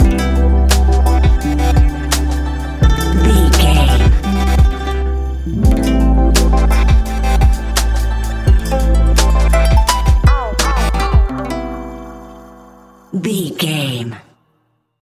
Ionian/Major
Slow